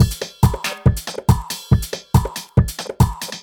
Category 🎵 Music
beat beats drumkit fast Gabber hardcore House Jungle sound effect free sound royalty free Music